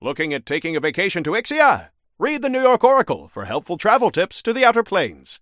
Newscaster_headline_66.mp3